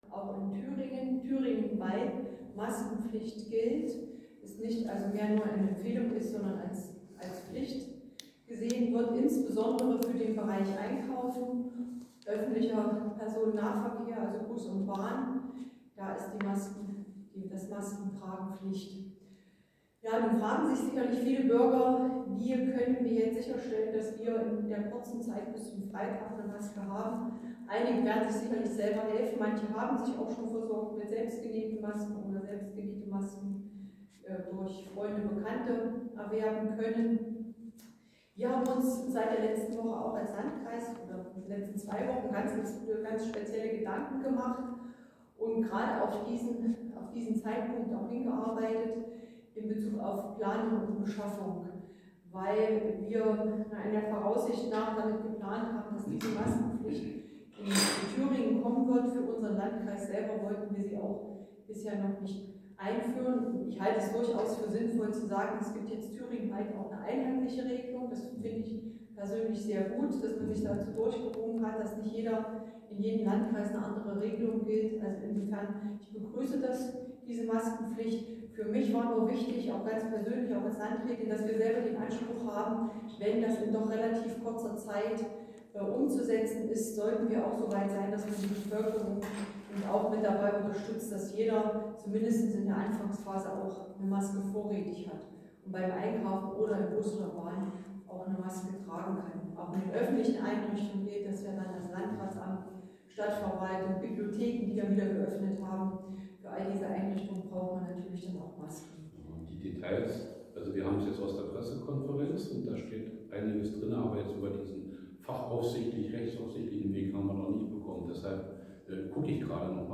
Hier das zugehörige Video von der Pressekonferenz: